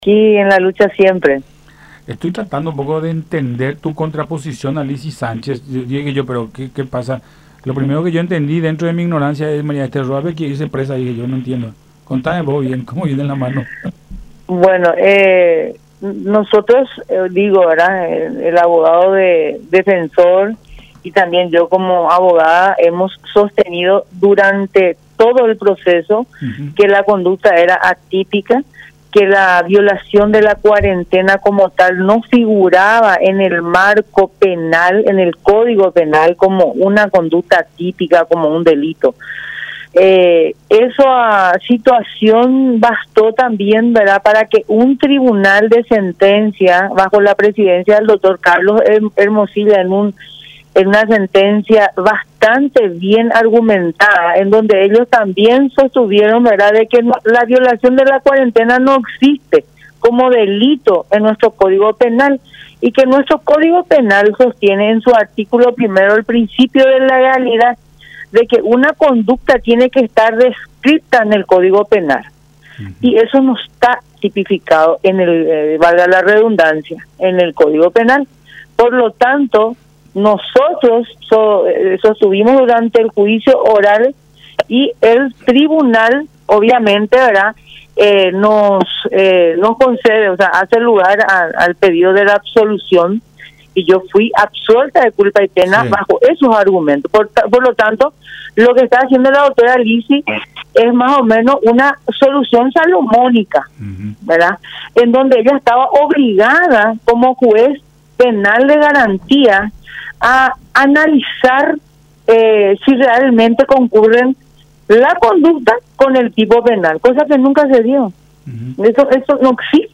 en diálogo con Buenas Tardes La Unión